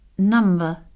Escolta com es pronuncia nom